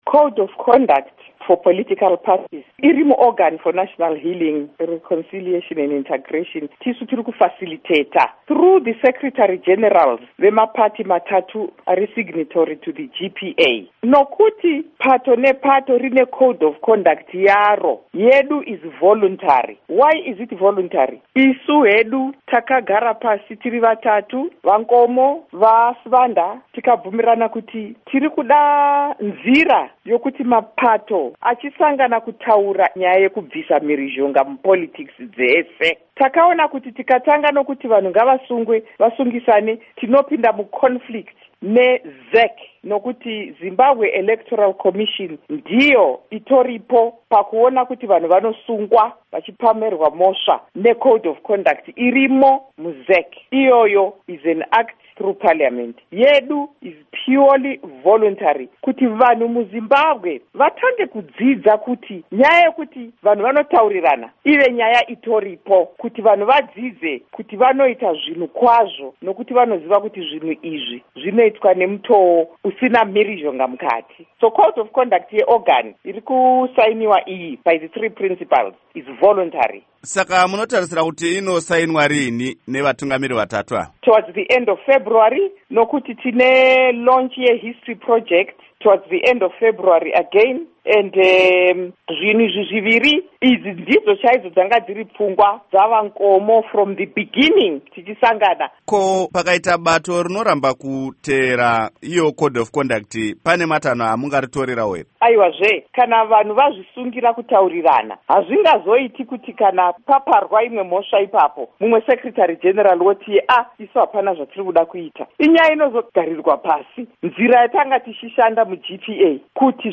Hurukuro naMai Sekai Holland